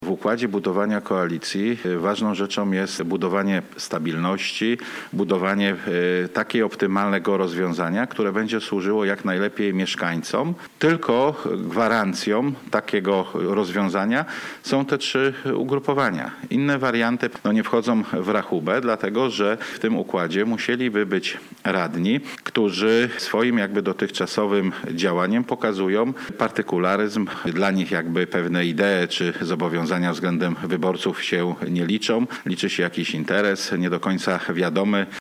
Konferencja z liderami PSL i LEWICY została zwołana w celu pokazania jedności koalicji rządzącej woj. lubuskim i zakończenia dyskusji o możliwej zmianie w Zarządzie Województwa.
Poseł Bogusław Wontor, przewodniczący Rady Wojewódzkiej SLD, potwierdził jedność poglądów koalicyjnych: